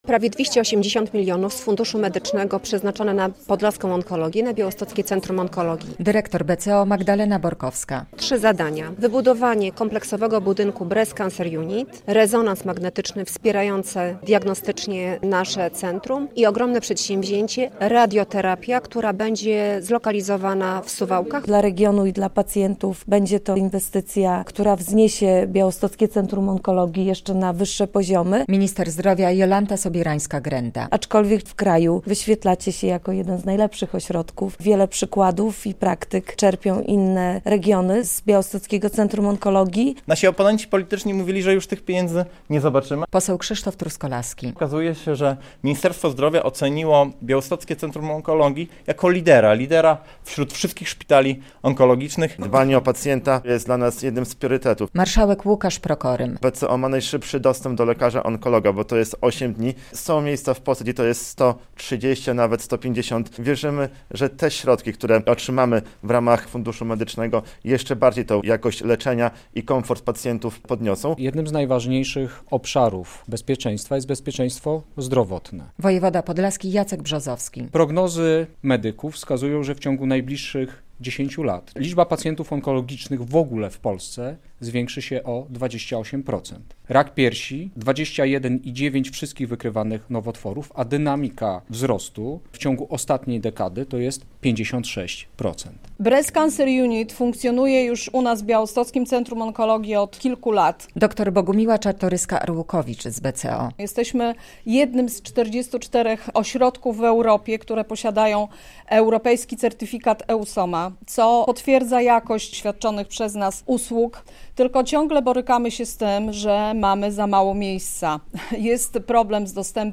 220 mln zł z Funduszu Medycznego ma dostać Białostockie Centrum Onkologii na ośrodek profilaktyki i leczenia raka piersi i utworzenie zakładu radioterapii w Suwałkach - poinformowano w piątek (3.10) na konferencji prasowej w Białymstoku z udziałem minister zdrowia Jolanty Sobierańskiej-Grendy.
relacja